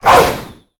Cri de Fulgudog dans Pokémon HOME.